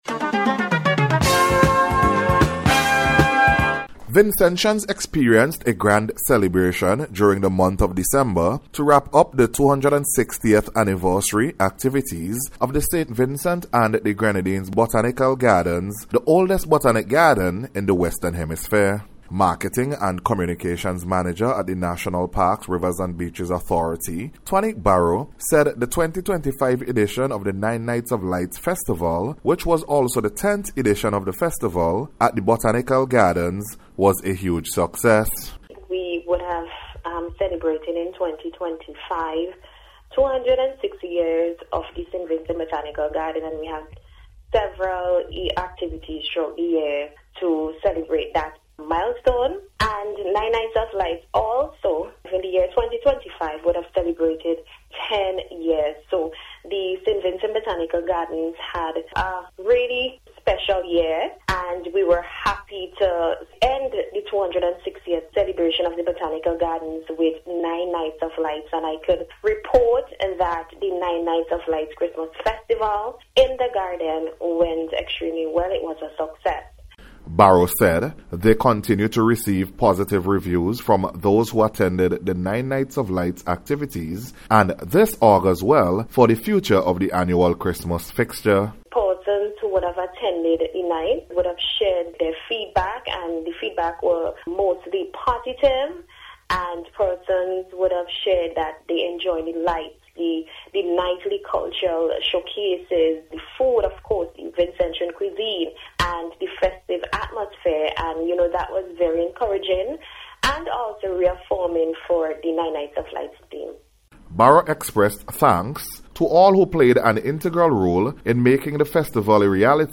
NBC’s Special Report- Wednesday 7th January,2026
GARDENS-260TH-ANNIVERSARY-HUGE-SUCCESS-REPORT.mp3